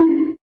BONK.mp3